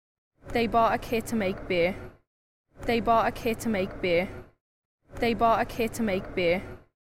The following sound file illustrates a speaker who has no BOUGHT-raising but has R-deletion and T-glottalisation.
Local speaker with (i) Open BOUGHT-vowel, T-glottalisation and no rhoticity (R-deletion)
BOUGHT_BEER_(local_speaker).mp3